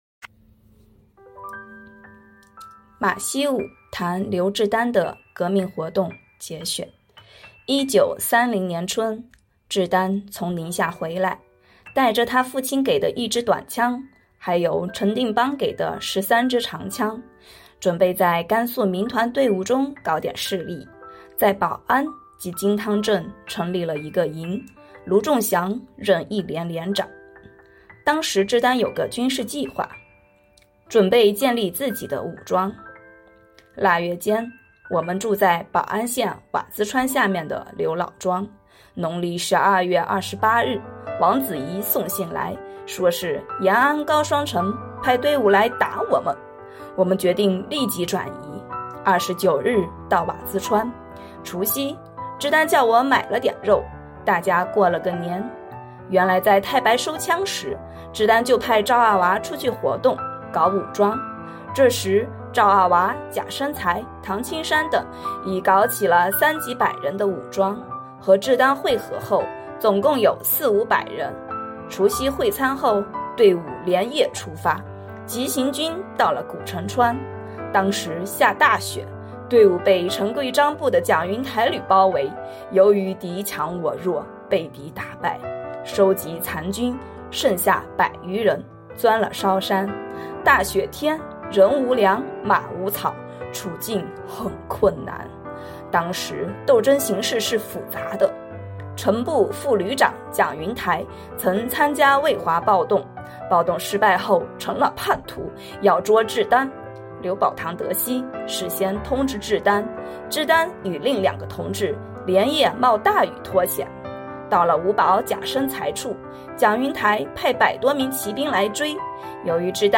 【红色档案诵读展播】马锡五谈刘志丹的革命活动（节选）